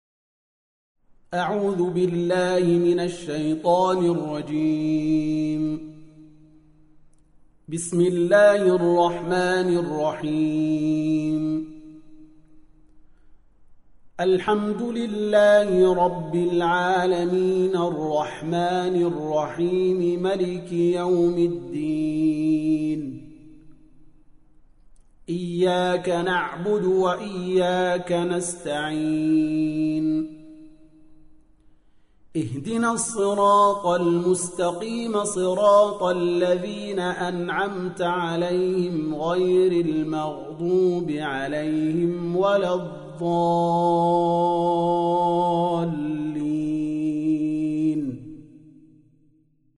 Surah Repeating تكرار السورة Download Surah حمّل السورة Reciting Murattalah Audio for 1. Surah Al-F�tihah سورة الفاتحة N.B *Surah Includes Al-Basmalah Reciters Sequents تتابع التلاوات Reciters Repeats تكرار التلاوات